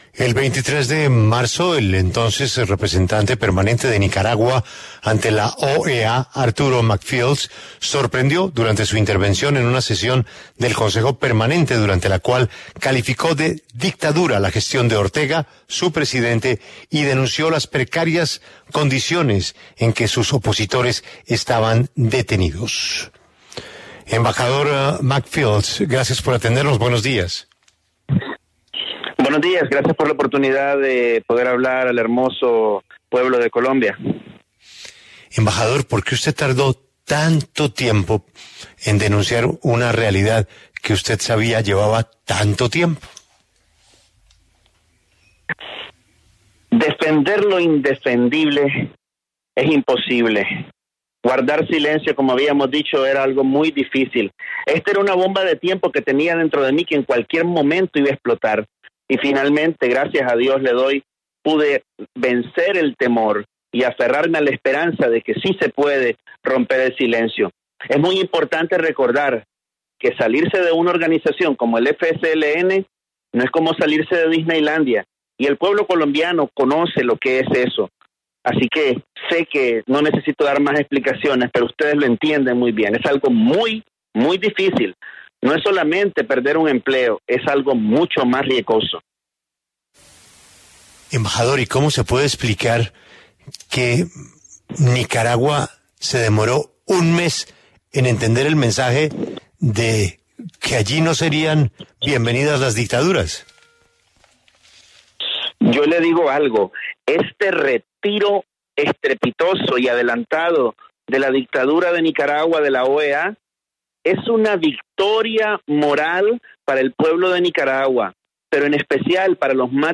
Arturo McFields, antiguo representante de Nicaragua ante la OEA, habló en La W a propósito de la expulsión en Managua y el retiro anticipado del país ante ese organismo.
En el encabezado escuche la entrevista completa con Arturo McFields, antiguo representante de Nicaragua ante la OEA.